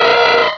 Fichier:Cri 0309 DP.ogg — Poképédia
Cri_0309_DP.ogg